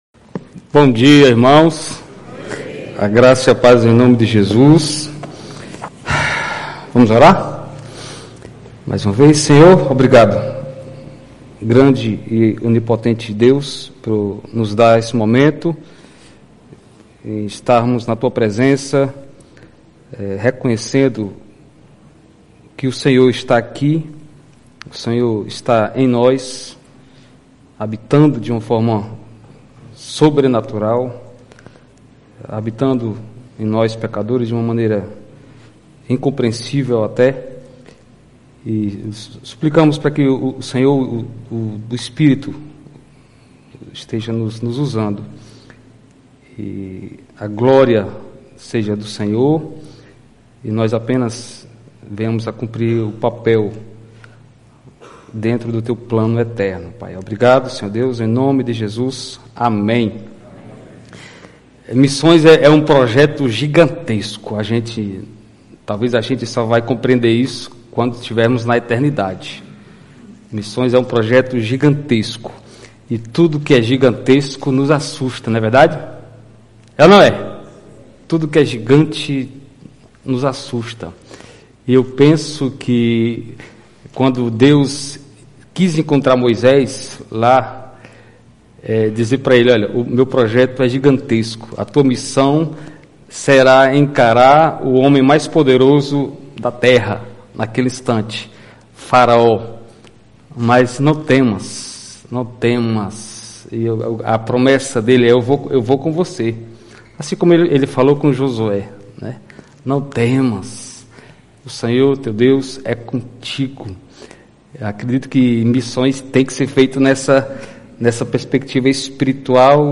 Igreja Batista Luz do Mundo, Fortaleza/CE.
Pregação